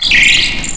Televersement cris 5G.